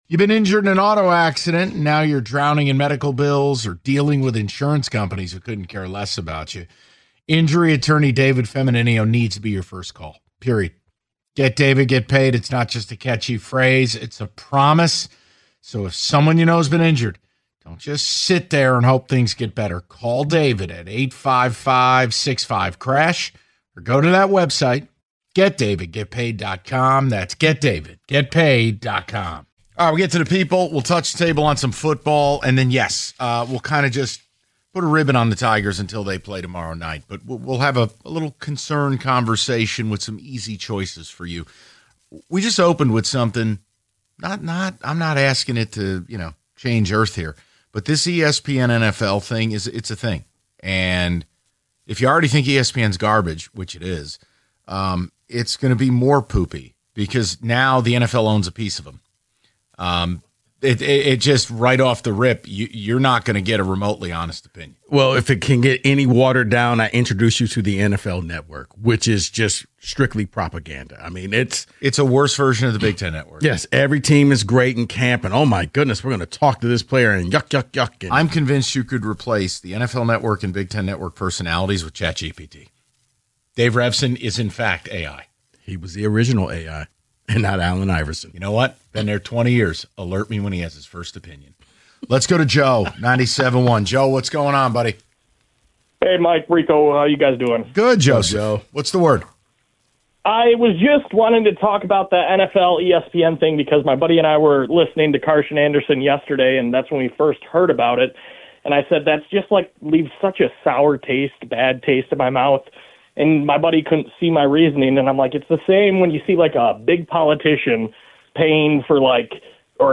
Taking Your Calls On ESPN/NFL Deal